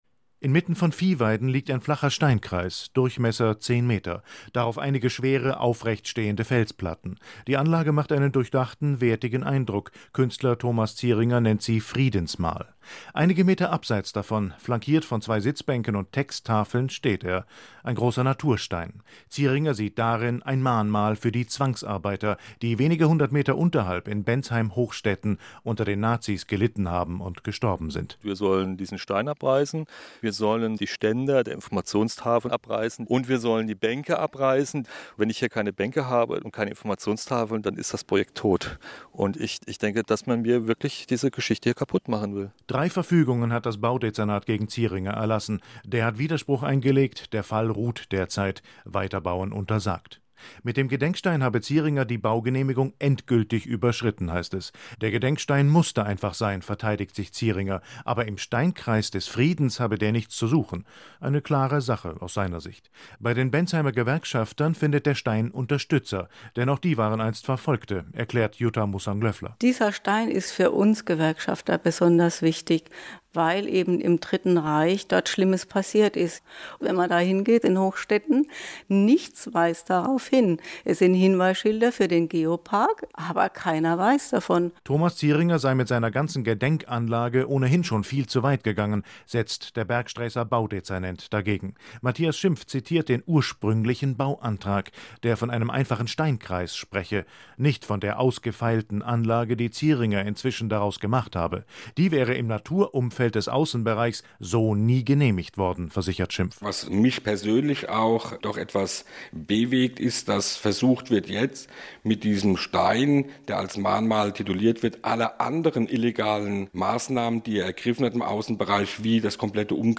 Radio Report (Hessischer Rundfunk) on the Demolition Order  (April 7, 2014)
hr-interview.mp3